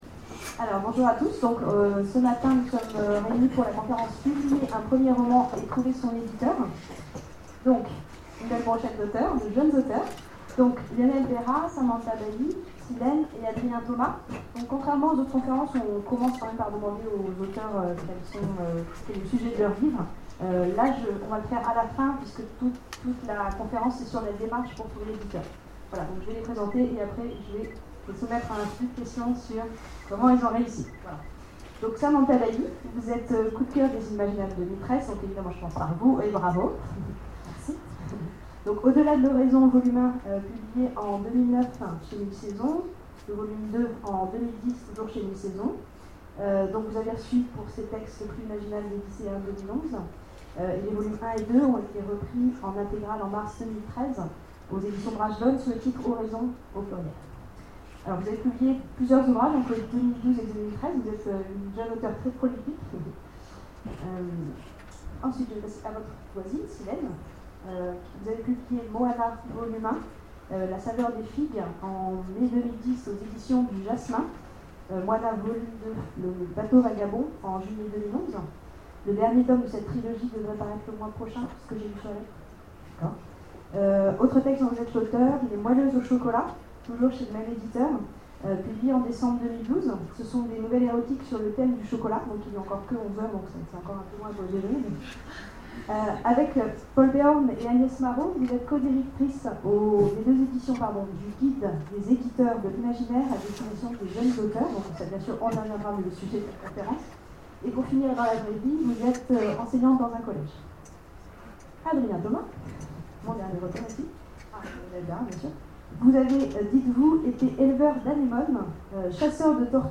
Imaginales 2013 : Conférence Publier un premier roman...